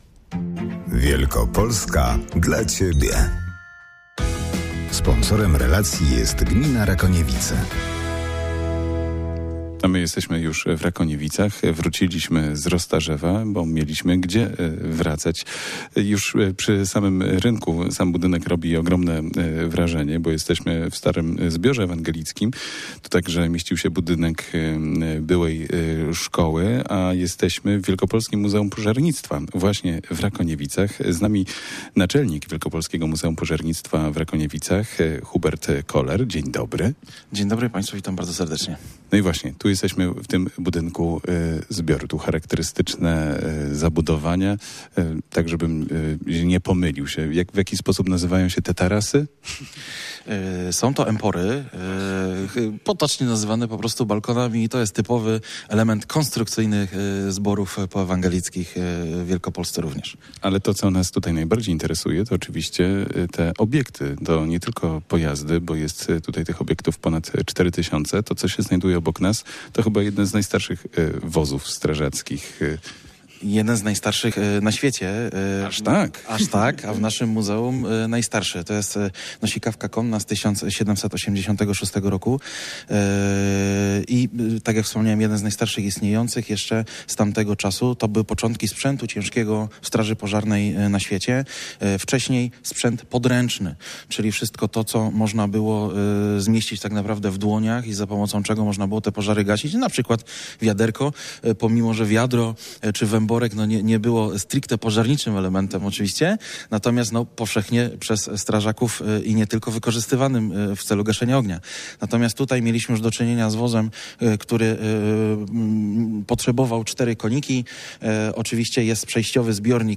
O historii pałacu i jego gruntownym remoncie opowiadał burmistrz Rakoniewic dr Gerard Tomiak.